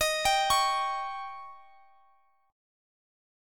Listen to D#7 strummed